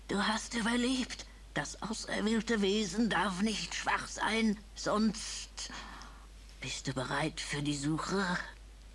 Audiozitat aus Fallout 2, das aus dem entspr. Videomitschnitt extrahiert wurde.